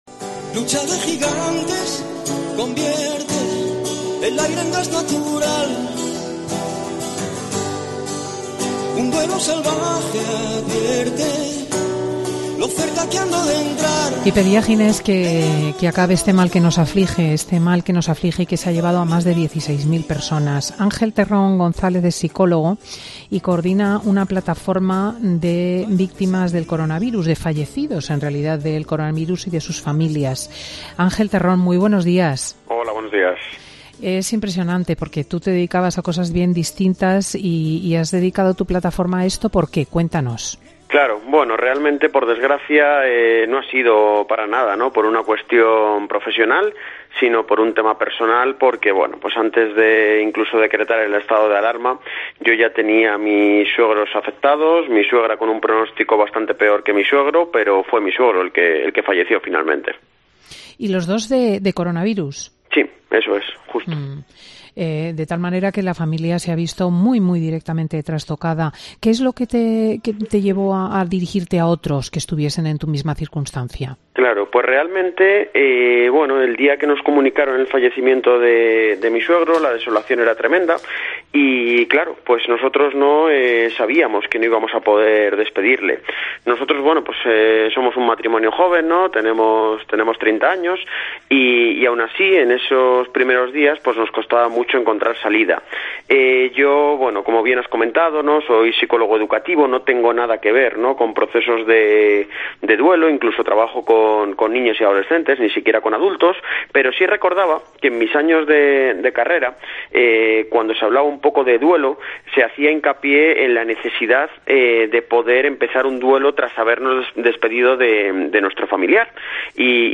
Dos expertos hablan de los fallecimientos en la avalancha del coronavirus